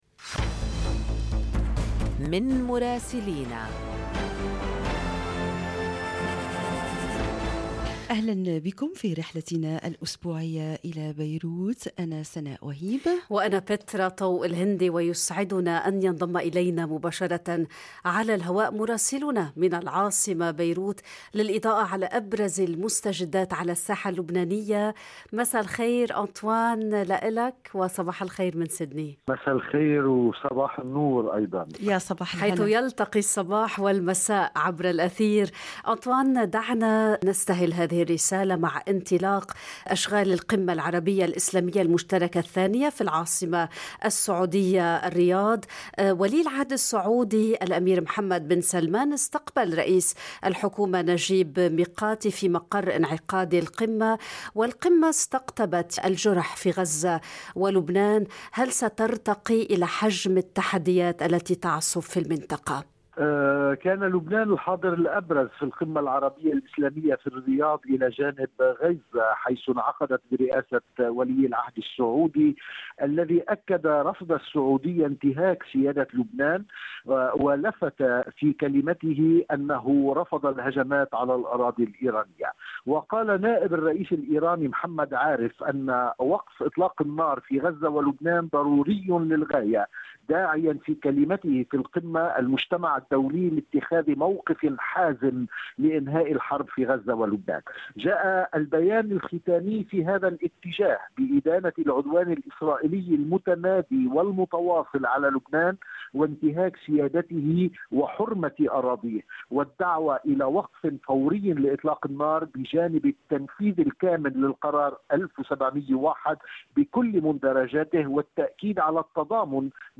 يمكنكم الاستماع إلى تقرير مراسلنا في العاصمة بيروت بالضغط على التسجيل الصوتي أعلاه.